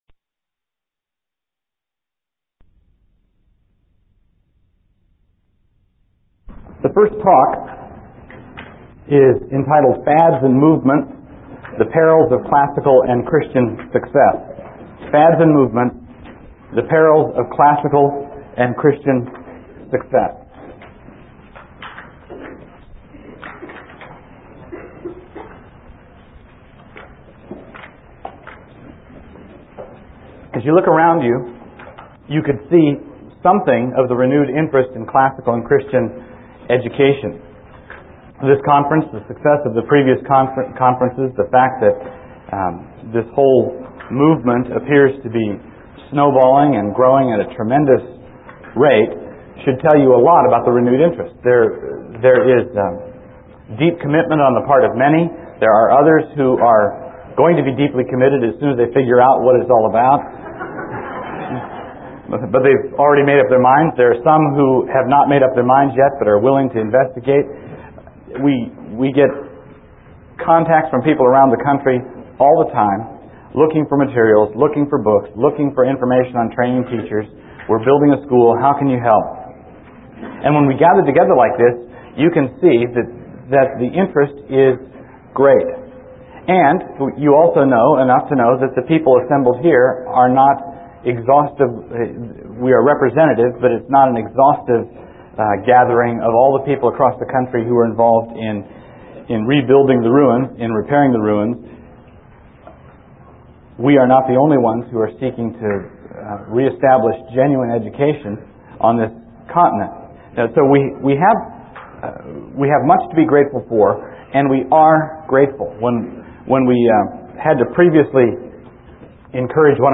1996 Workshop Talk | 0:59:05 | All Grade Levels, Culture & Faith